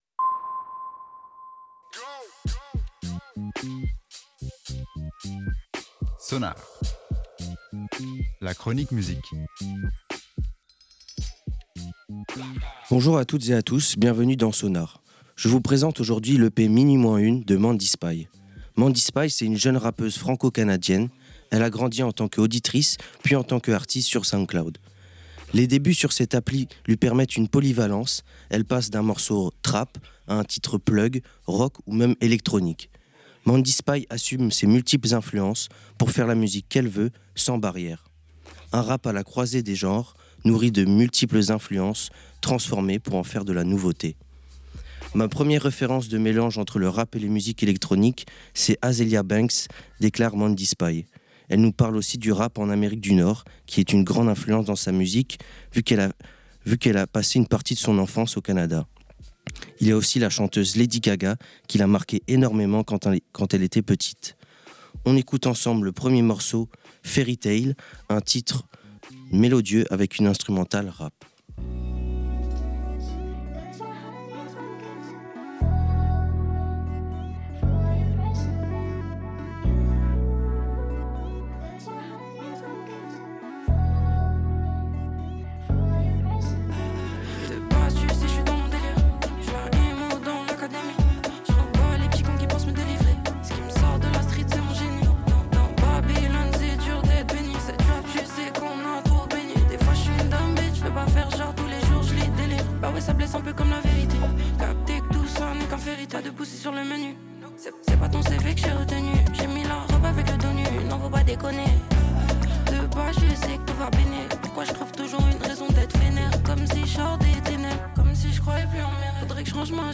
rap francophone